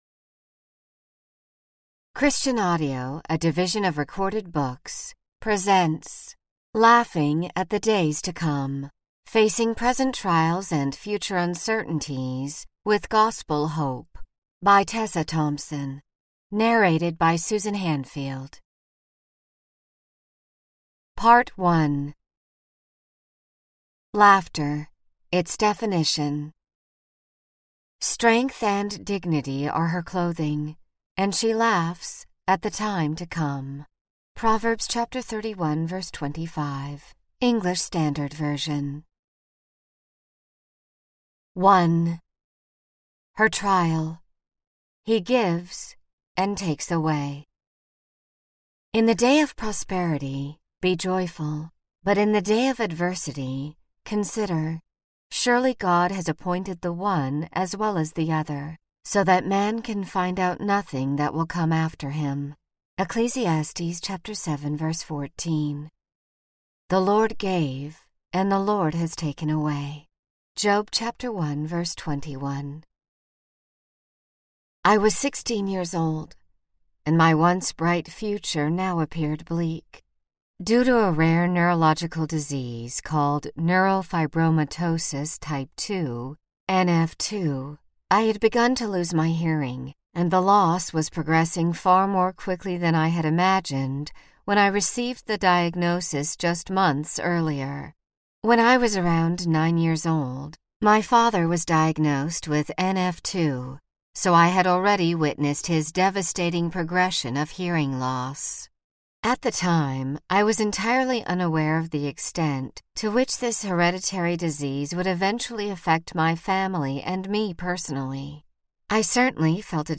Narrator
7.2 Hrs. – Unabridged